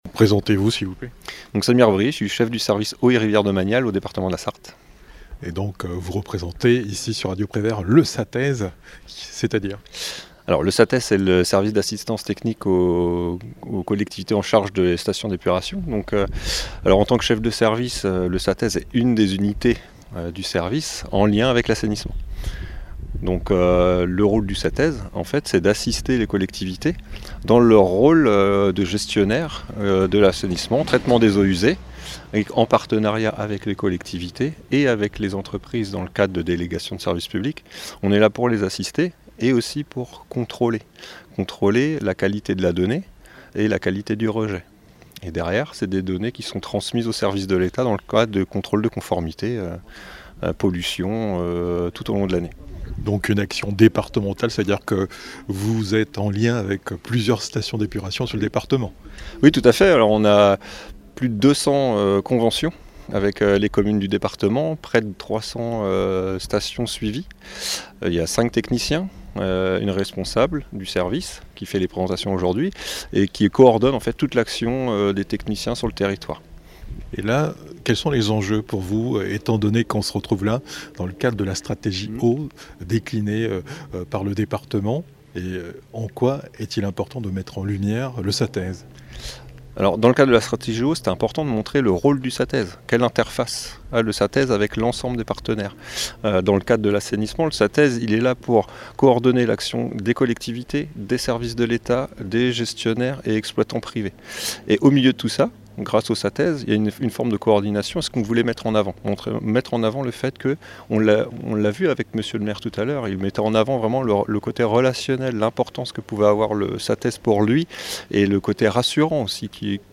Visite station d'épuration Yvré-Le-Pôlin